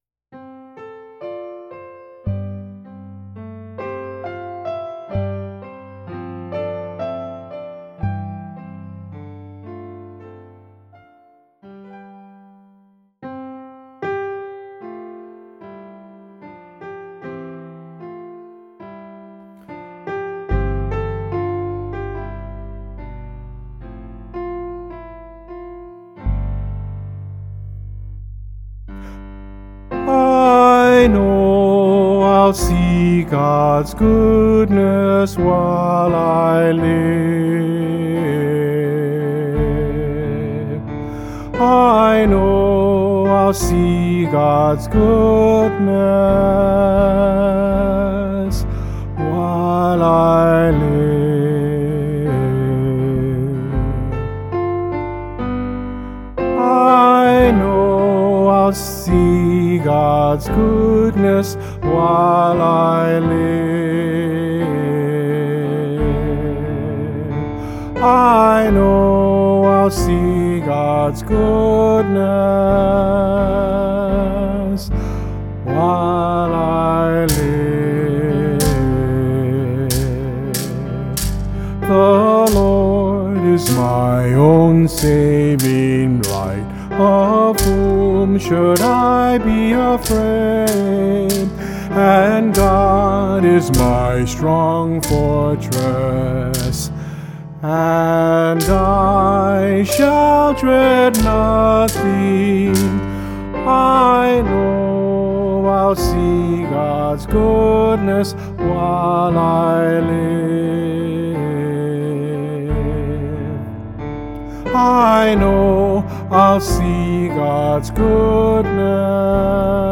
Instrumental | Downloadable